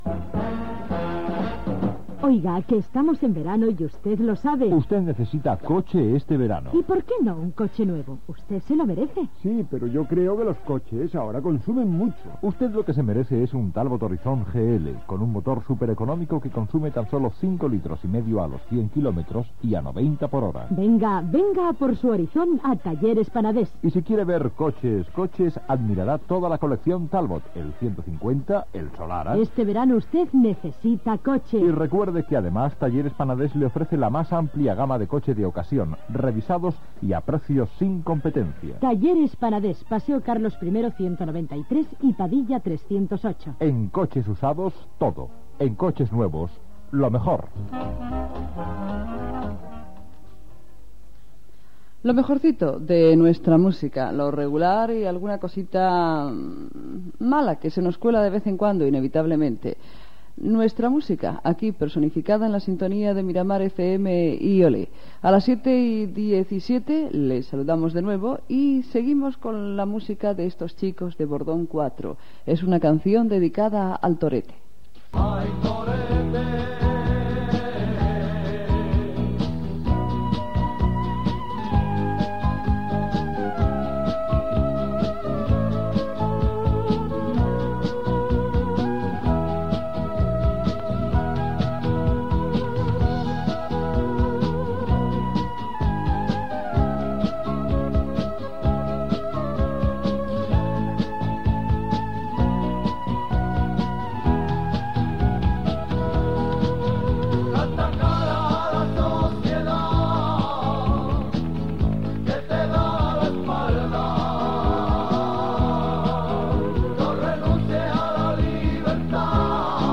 Presentació d'un tema musical.
Musical
Presentador/a